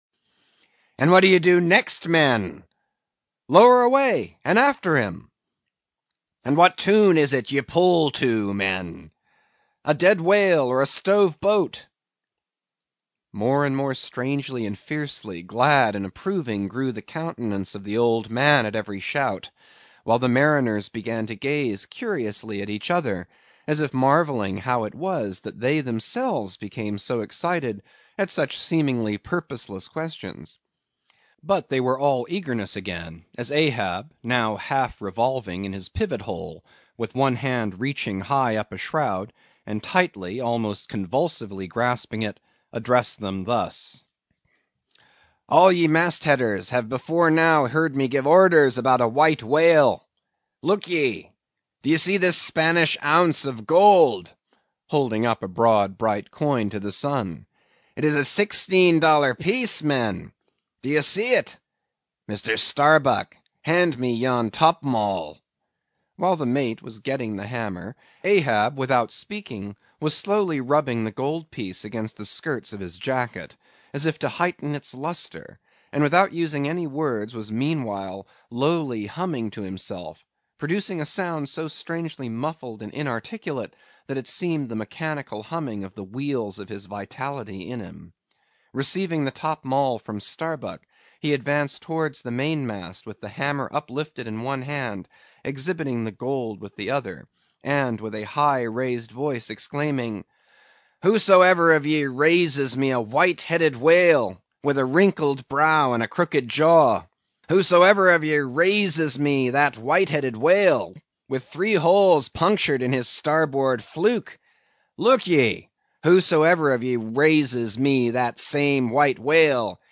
在线英语听力室英语听书《白鲸记》第412期的听力文件下载,故事以一条全身纯白的巨型鲸鱼莫比敌为中心发展，它是一条被水手们视为魔鬼化身的凶猛鲸鱼。船长阿哈伯在捕捉它的过程中被咬掉了一条腿，这种屈辱与伤痛更激发起他一定要打败这条巨鲸的决心。